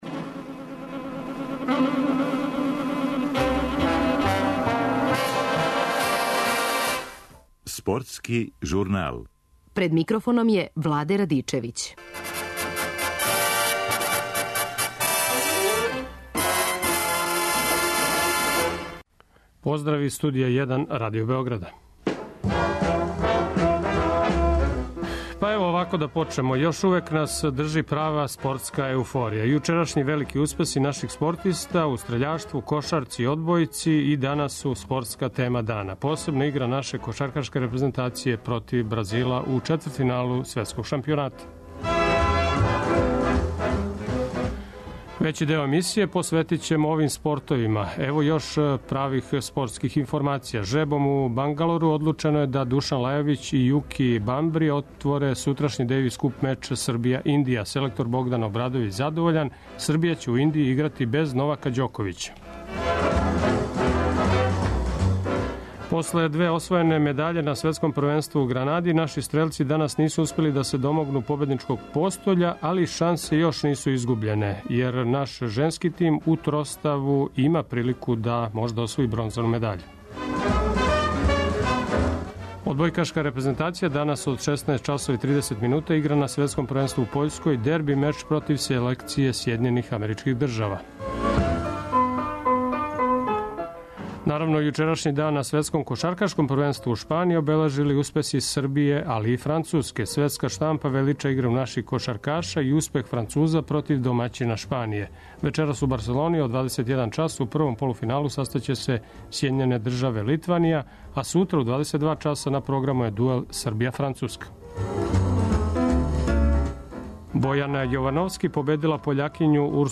Успех у Мадриду коментарише некадашњи ас Драган Капичић.